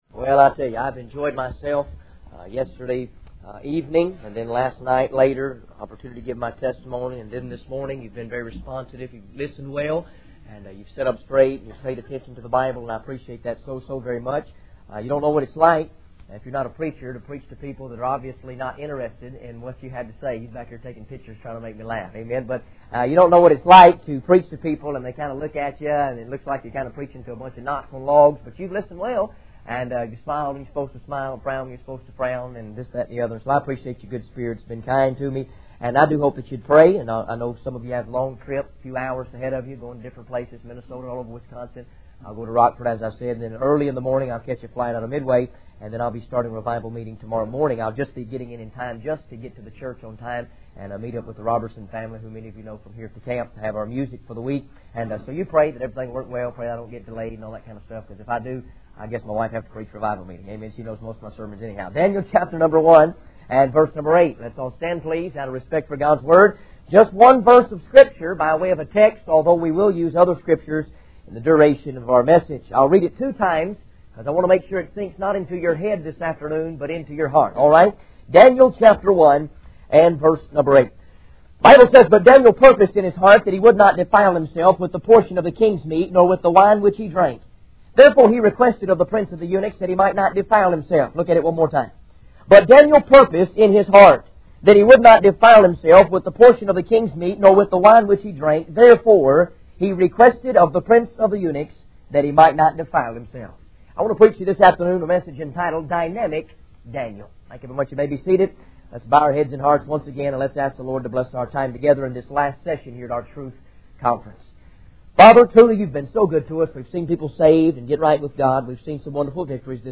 In this sermon, the preacher emphasizes the importance of being careful about what we watch and listen to, as it can have a negative impact on our hearts and minds.